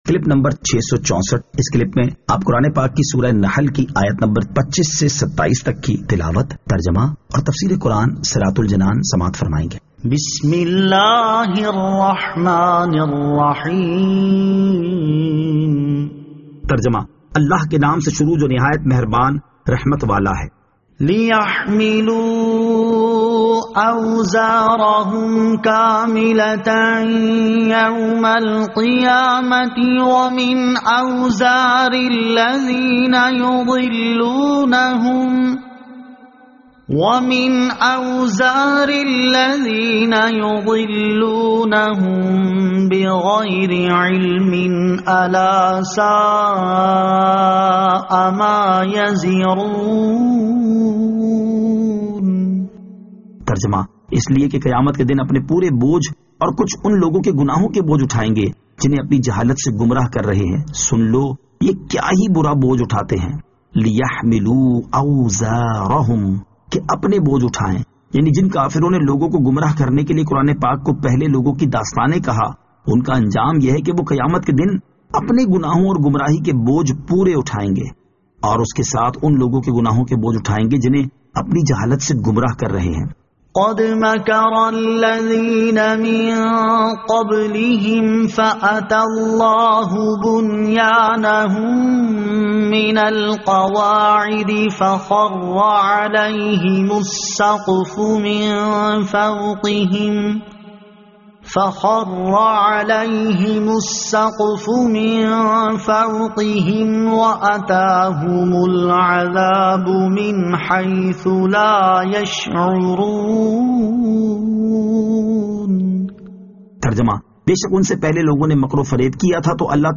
Surah An-Nahl Ayat 25 To 27 Tilawat , Tarjama , Tafseer